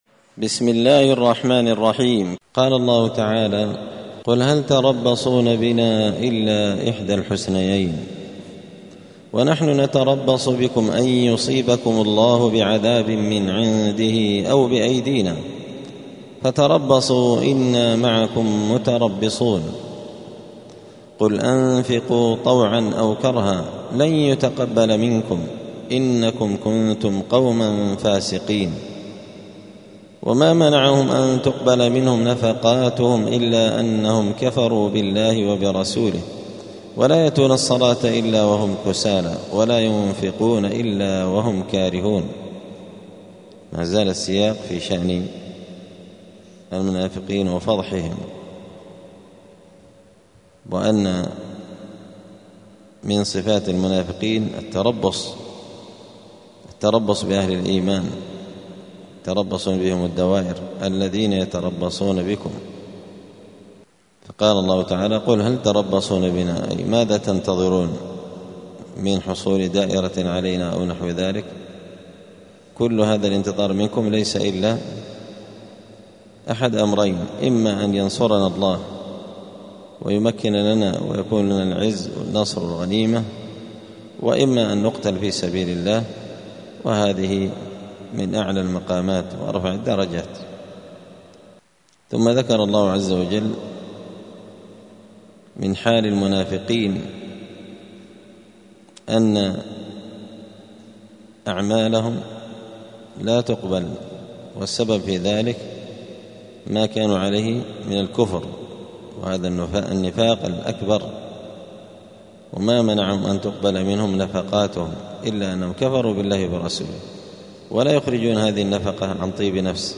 📌الدروس اليومية